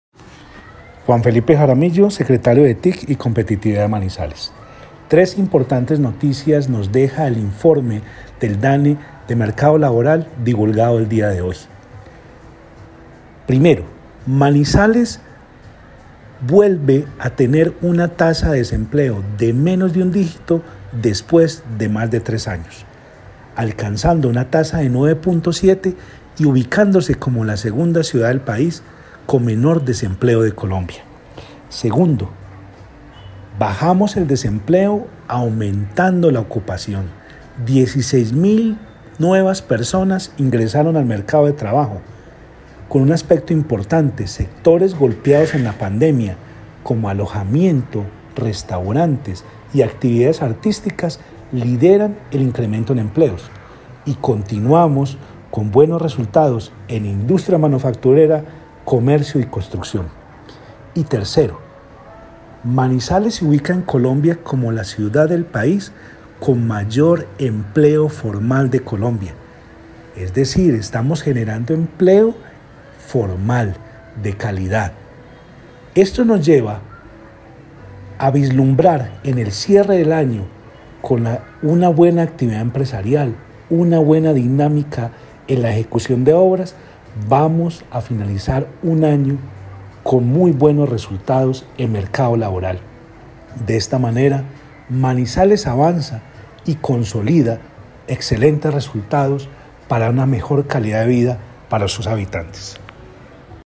Juan Felipe Jaramillo, secretario de TIC y Competitividad de Manizales.